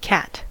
cat: Wikimedia Commons US English Pronunciations
En-us-cat.WAV